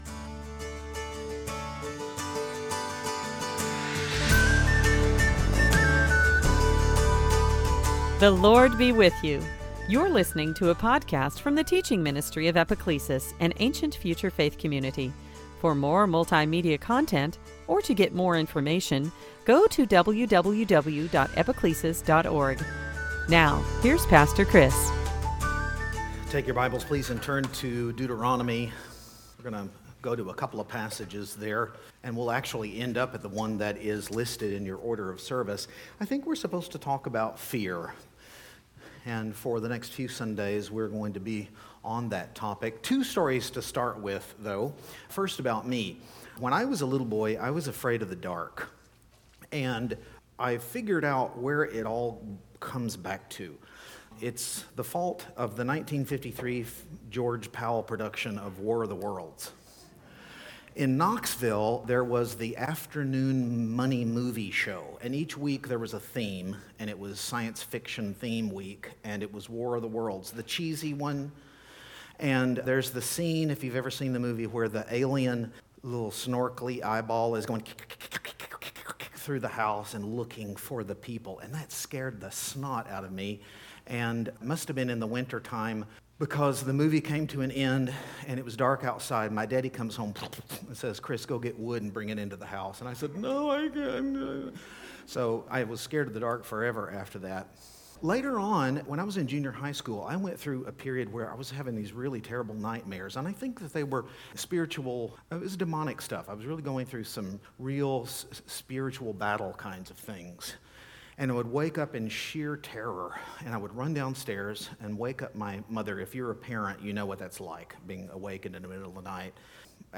We ended ended our time with a reading from the Passion translation of Psalm 91.
Series: Sunday Teaching